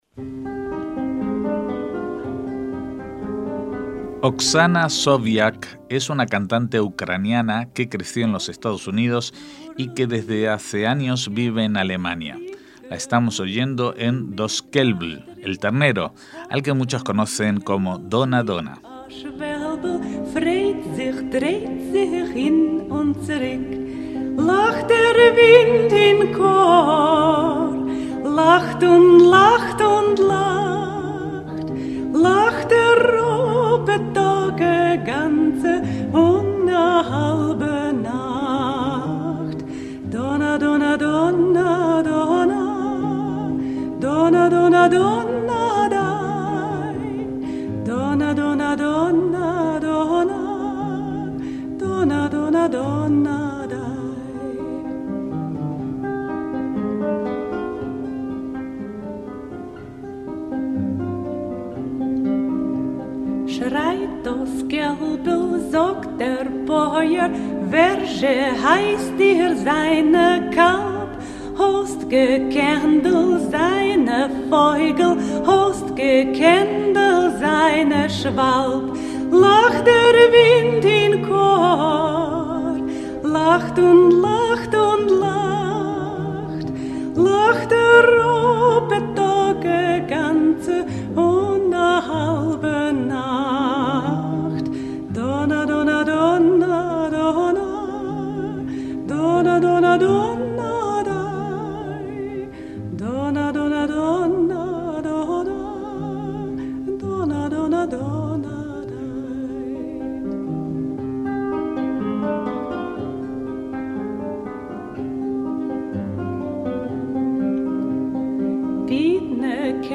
guitarrista
cantante ucraniana